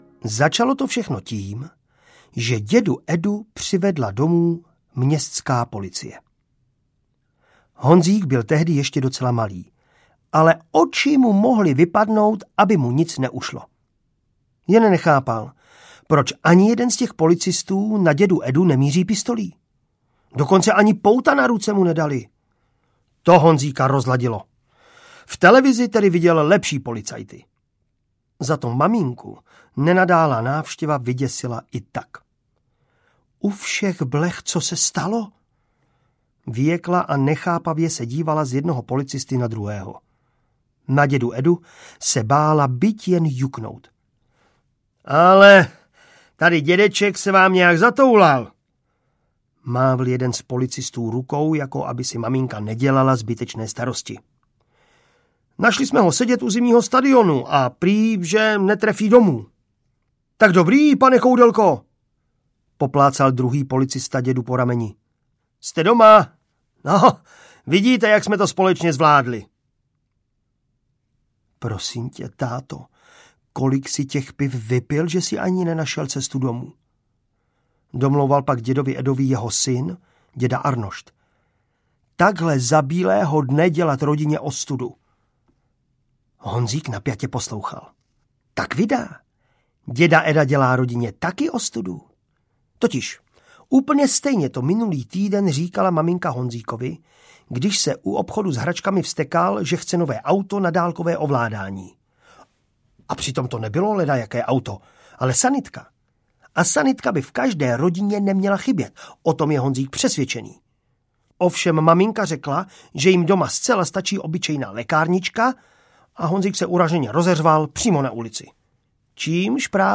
Lentilka pro dědu Edu a Trenky přes kalhoty audiokniha
Ukázka z knihy
Uslyšíte Martina Dejdara jako Honzíka a Bára Hrzánová vám přečte příběh z pohledu Honzíkovy maminky – Trenky přes kalhoty zatím nevyšly tiskem.
• InterpretMartin Dejdar, Barbora Hrzánová